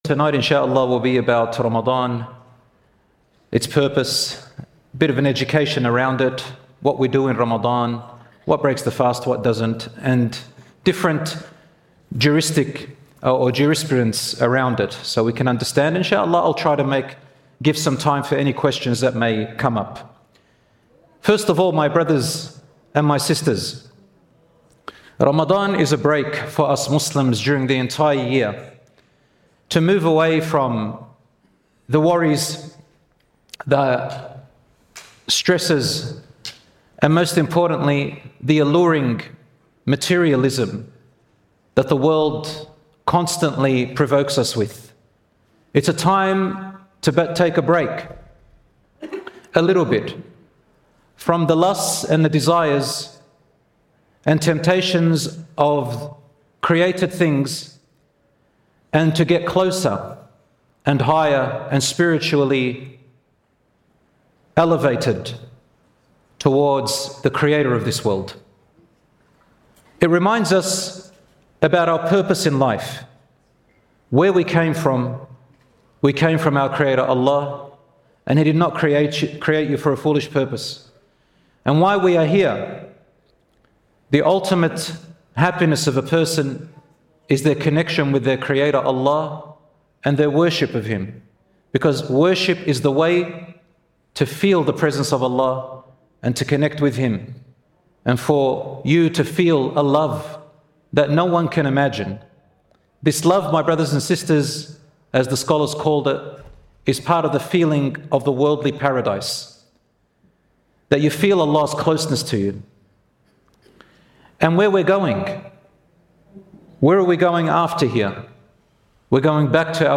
In this lecture, we discuss how Ramadan serves as a spiritual break from materialism, its history and purpose in Islam, the key acts to observe and avoid, the importance of intention, patience, and community, and the allowances for those unable to fast.